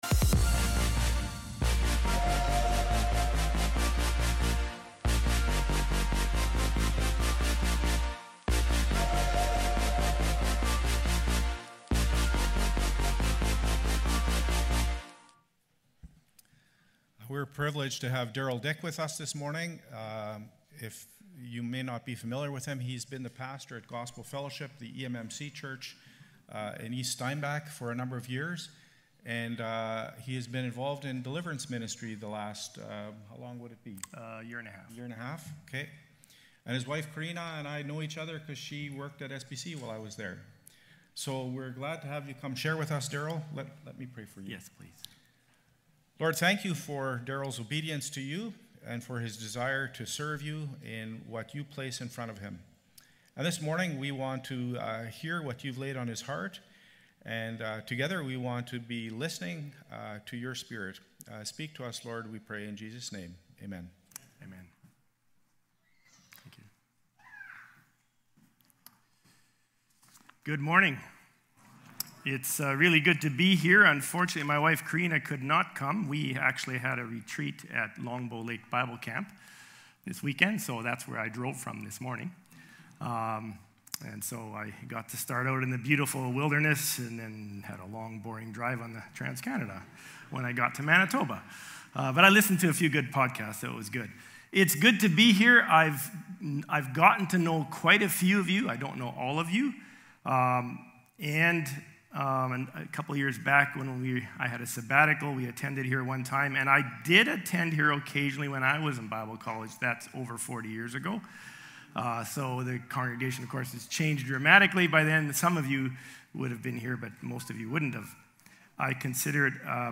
Aug-17-Worship-Service.mp3